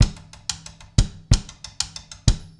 Stickrim loop2 92bpm.wav